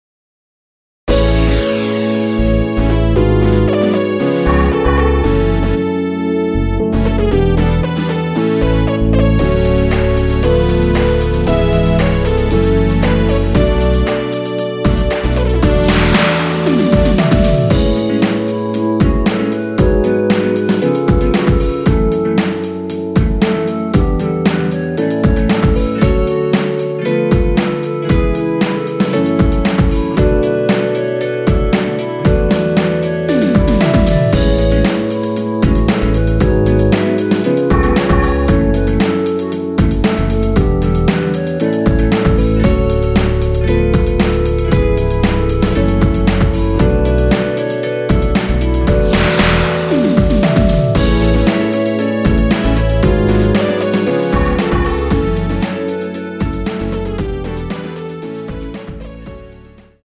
원키에서(-2)내린 멜로디 포함된 MR입니다.
멜로디 MR이라고 합니다.
앞부분30초, 뒷부분30초씩 편집해서 올려 드리고 있습니다.
중간에 음이 끈어지고 다시 나오는 이유는